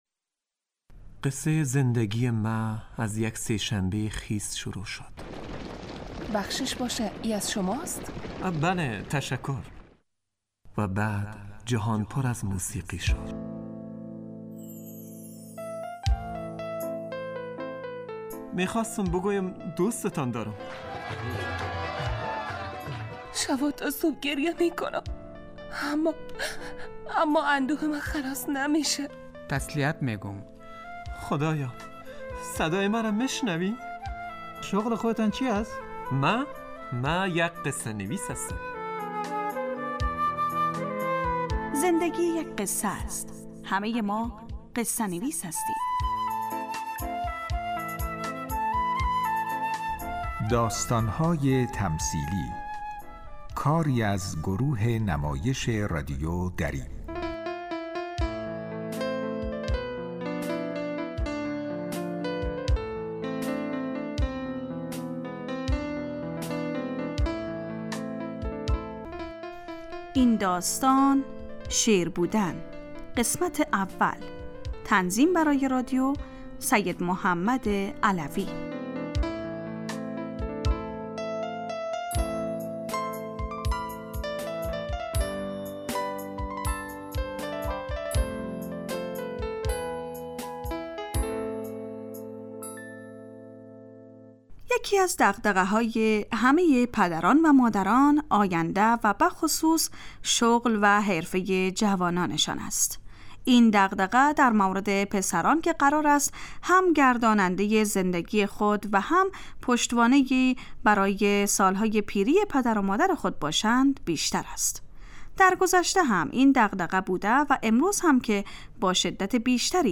داستانهای تمثیلی نمایش 15 دقیقه ای هستند